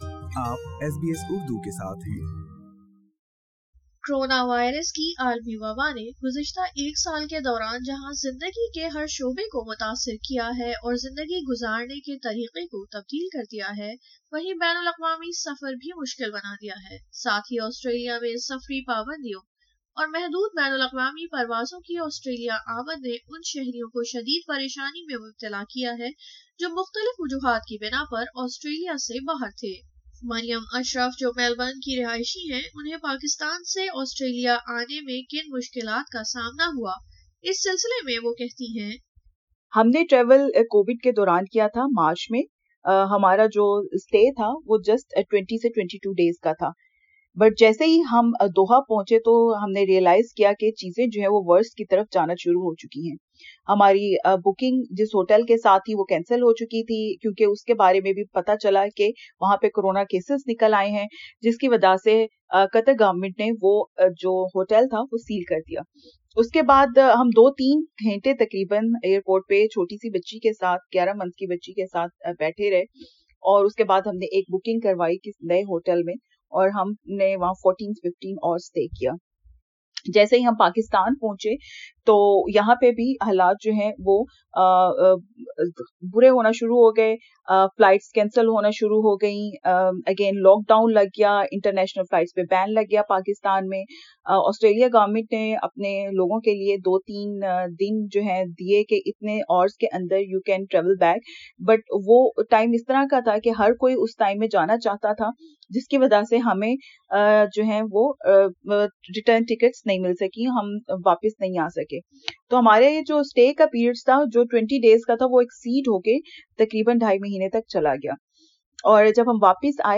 بین الالقوامی سفر سے واپس آنے والے مسافر پروازوں میں تاخیر، پروازوں کی منسوخی اور قرنطینہ سمیت کئی مشکلات سے دوچار ہیں ۔ اس سلسلے میں مختلف خواتین کا تجربہ کیسا رہا سنئیے اس پوڈ کاسٹ میں ۔